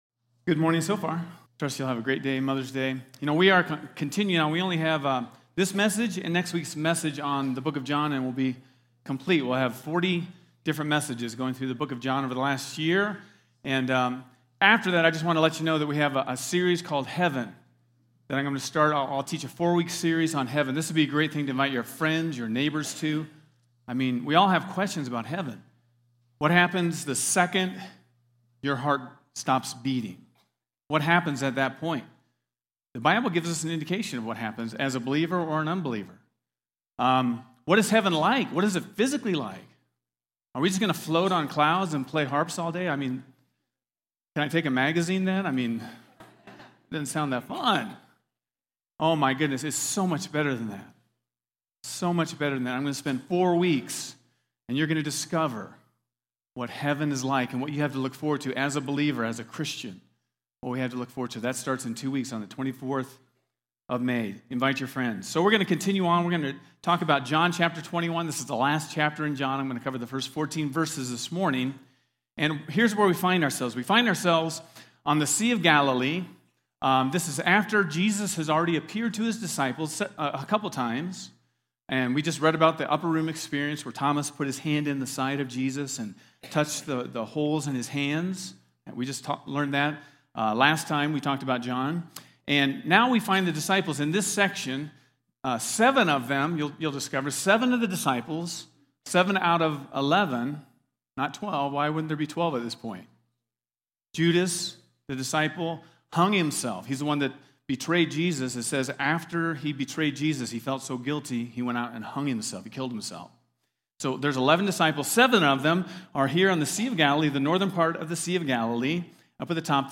Get Wisdom: Practical Wisdom for Life from the Book of Proverbs, is a 14-week sermon series from The Rock Church in Draper Utah.